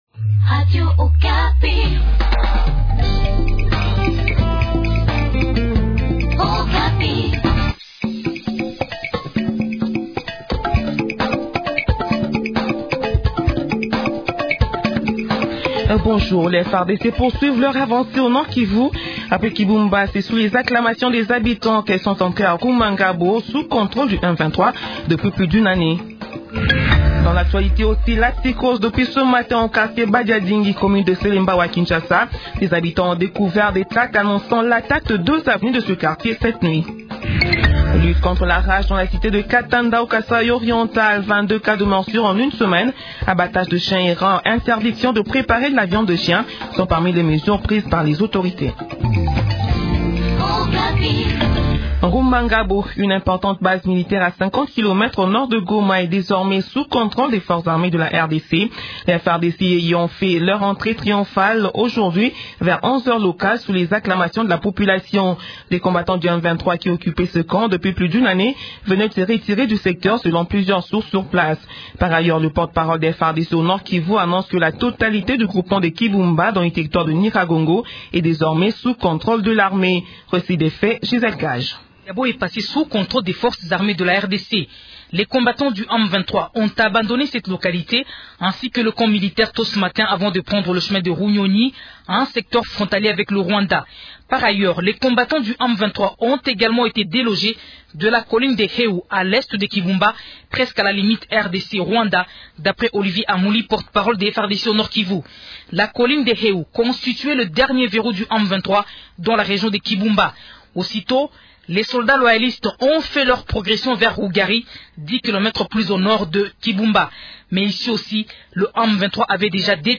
Journal Français Midi